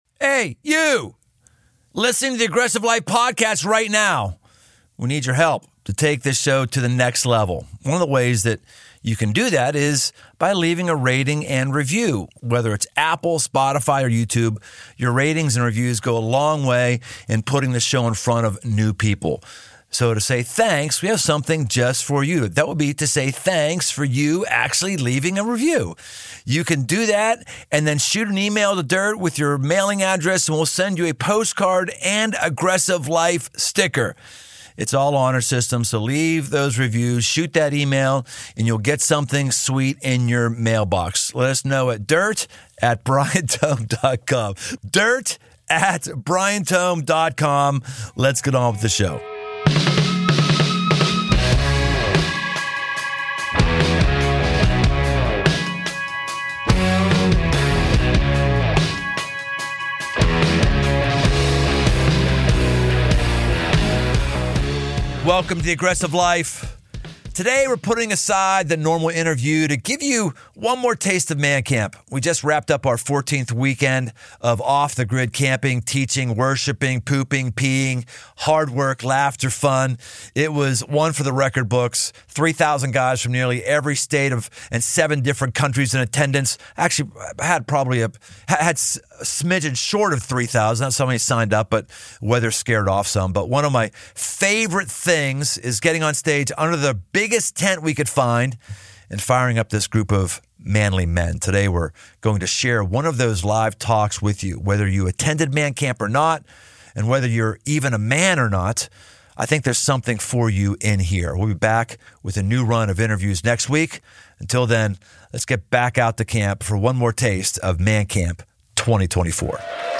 When you try to take new ground, it means one thing: there will be a fight. Recorded under the big tent at MAN CAMP 2024